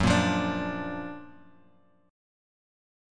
Gb+M7 chord